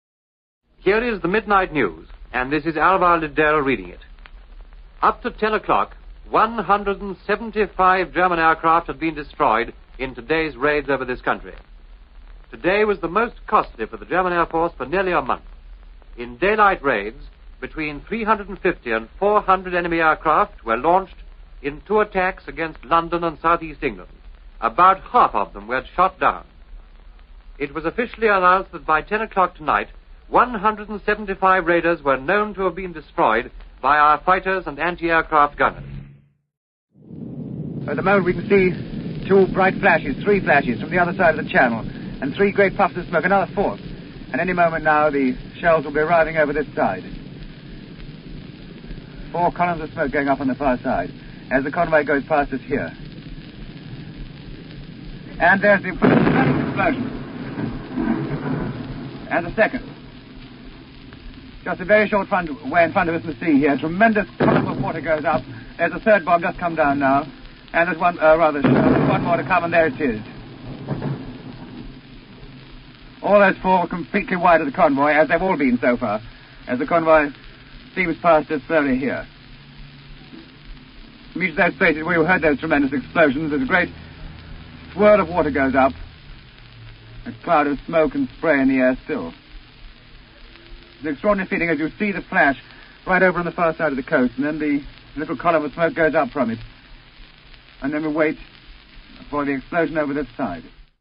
Listen to Alvar Lidell reading the Midnight News during a vital stage of the Battle of Britain (1940)
report